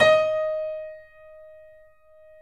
Index of /90_sSampleCDs/E-MU Producer Series Vol. 5 – 3-D Audio Collection/3D Pianos/YamaHardVF04